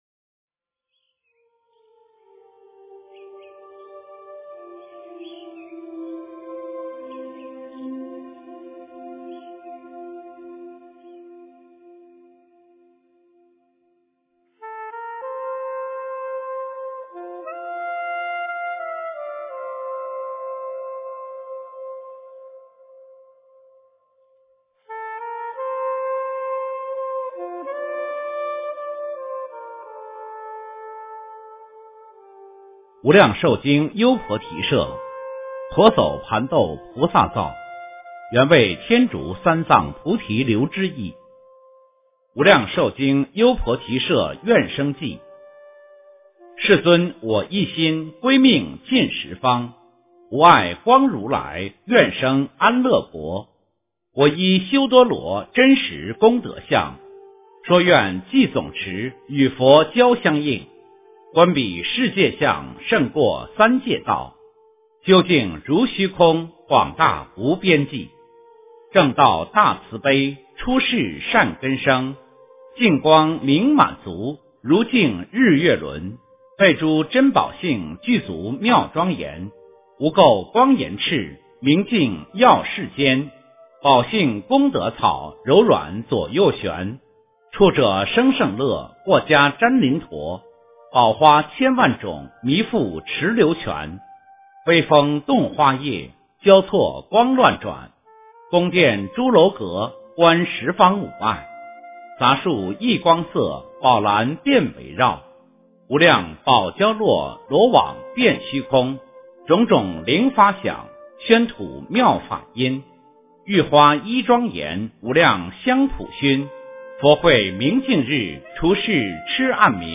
往生论 - 诵经 - 云佛论坛